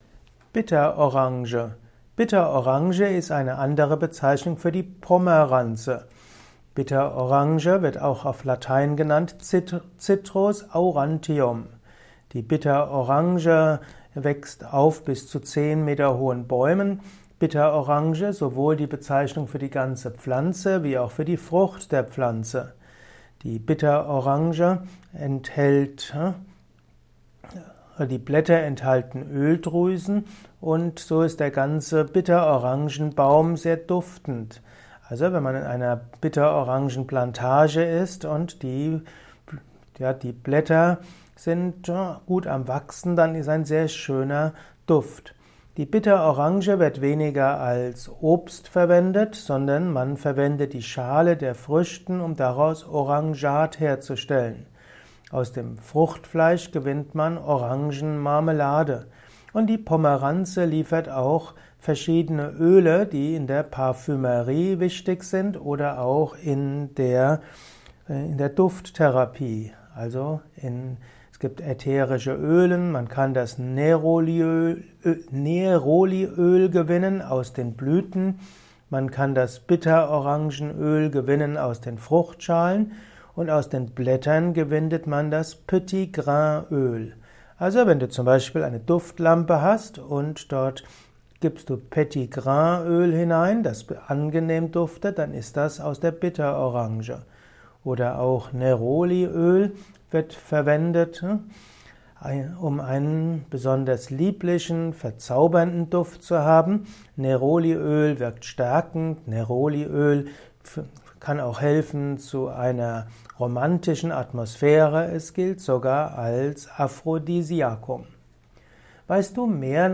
Kompakte Informationen zu Bitterorangen in diesem Kurzvortrag